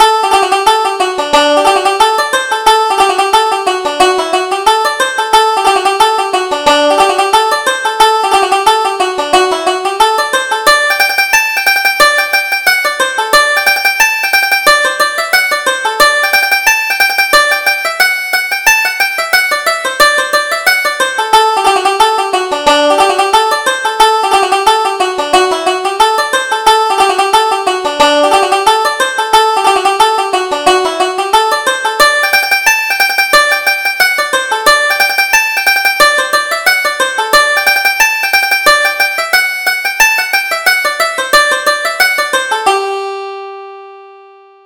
Reel: The Mountain Lark - 2nd Setting